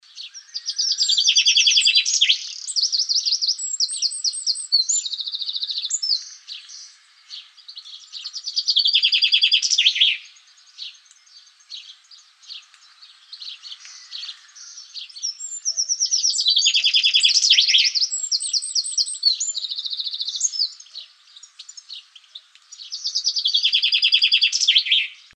Eurasian Chaffinch (Fringilla coelebs)
Sex: Male
Life Stage: Adult
Location or protected area: Botanic Garden de Cambridge
Condition: Wild
Certainty: Recorded vocal
chaffinch-2.MP3